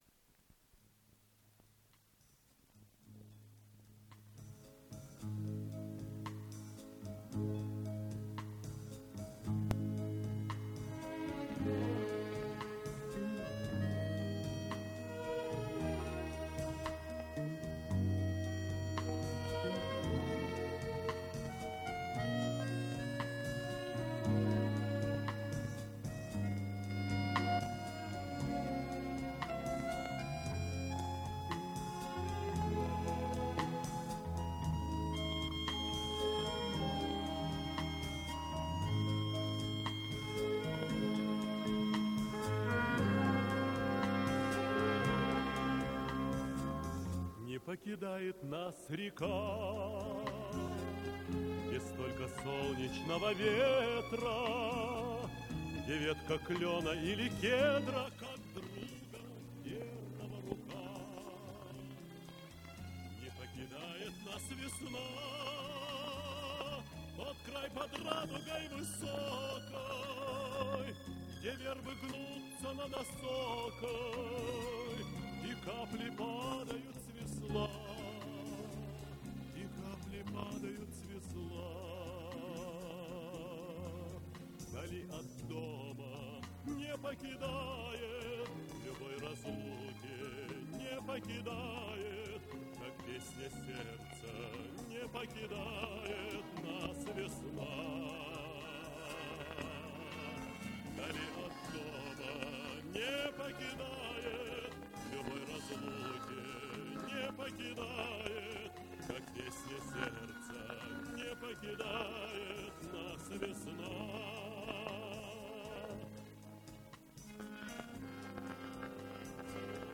Запись 1978 год Дубль моно.